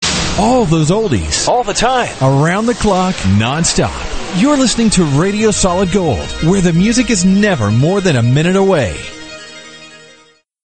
RADIO IMAGING